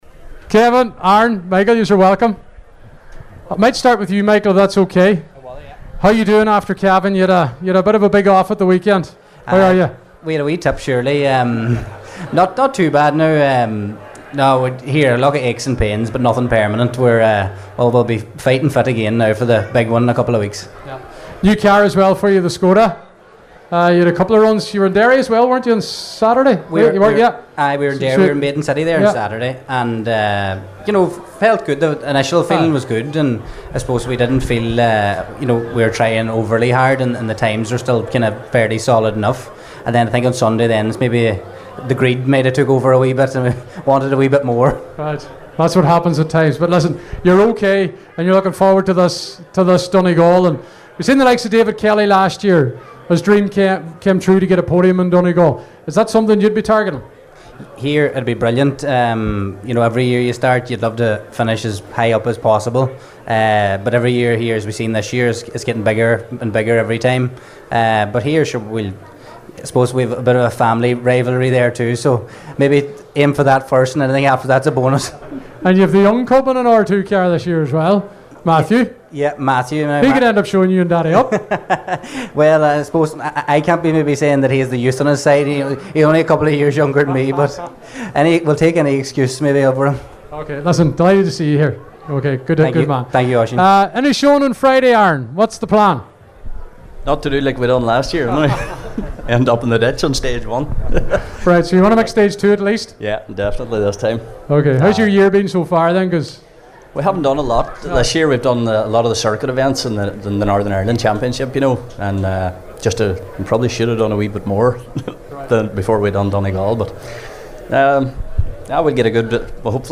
Donegal International Rally Launch night interviews